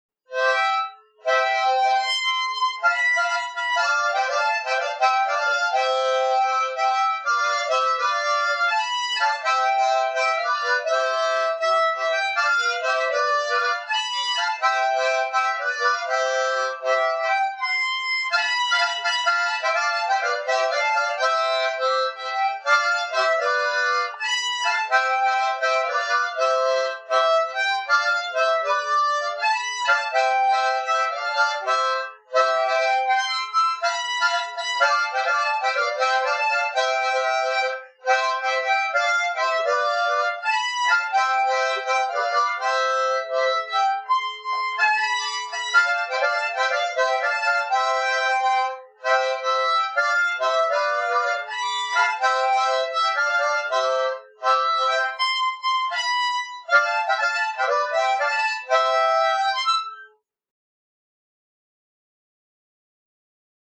Cueca interpretada en armónica
Música tradicional
Cueca